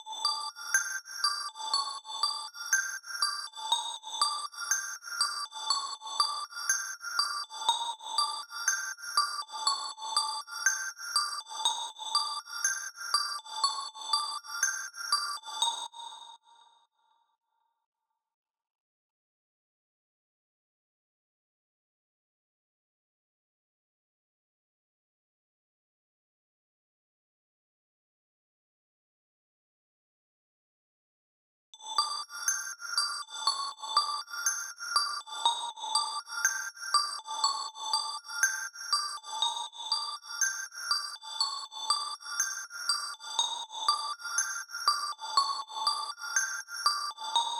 🔹 50 Premium Serum Presets crafted for melodic house, cinematic soundscapes, and deep emotional productions.
• Layered & Textured Sounds for that big cinematic feel
Preset Preview
RearView-Lights-0016-Instrument-ML-Candel-Click.wav